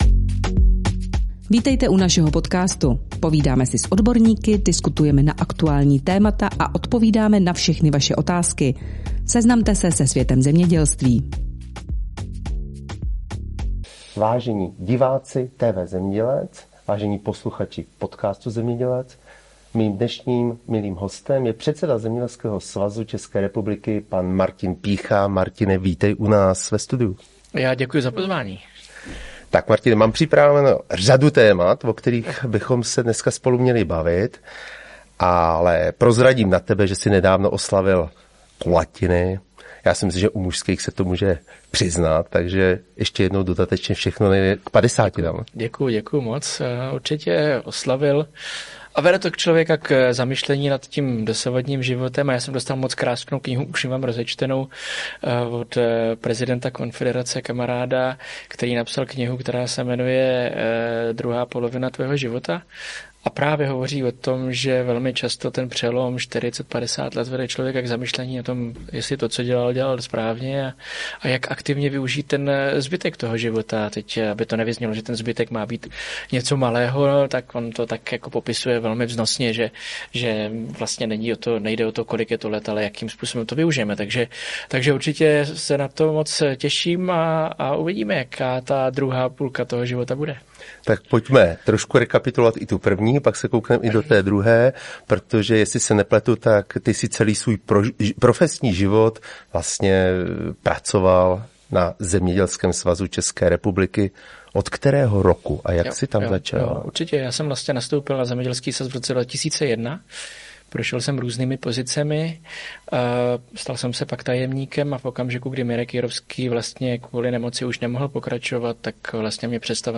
Tisková konference